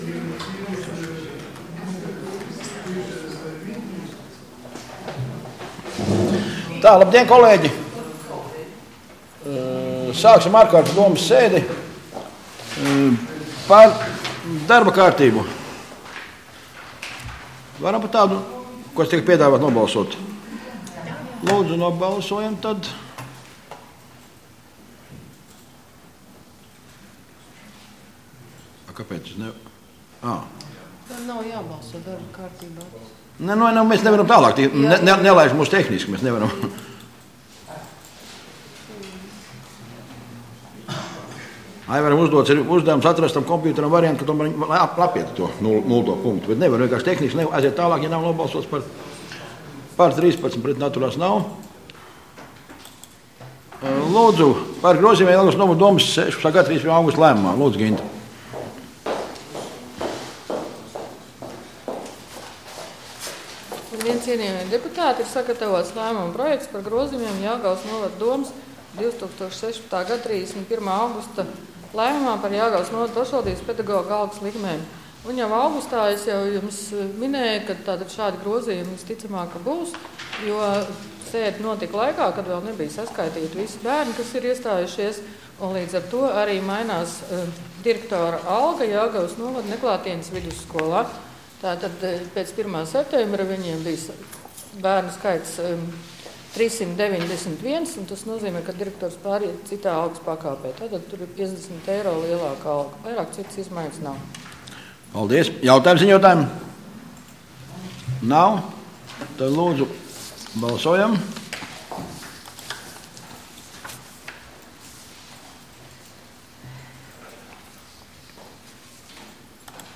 Domes ārkārtas sēde Nr. 15